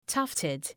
{‘tʌftıd}